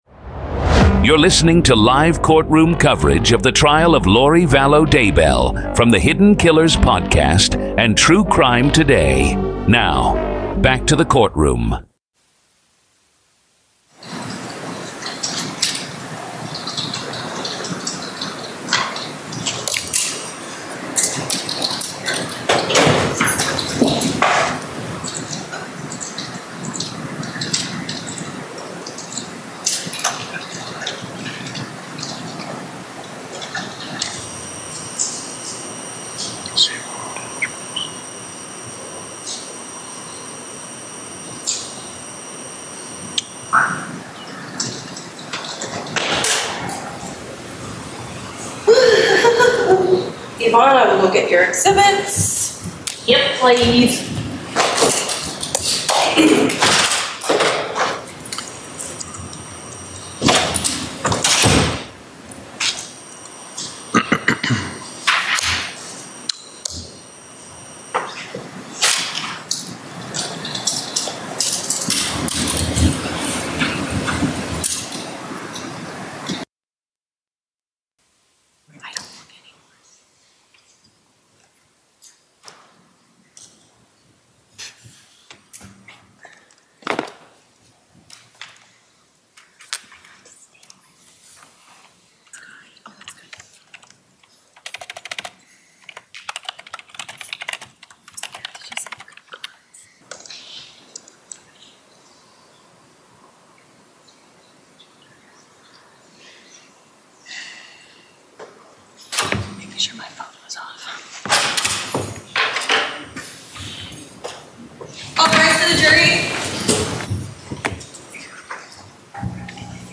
This is audio from the courtroom